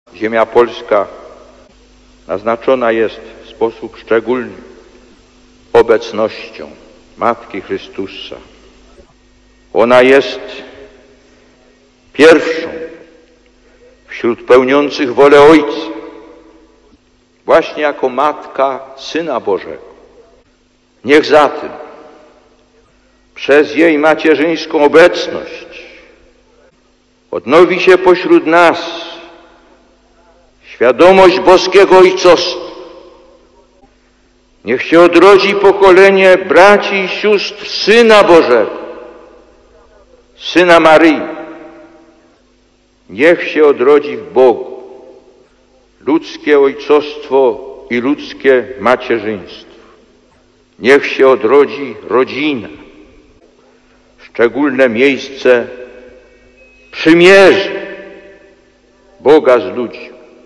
Głos Papieża: (